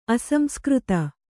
♪ aSamskřta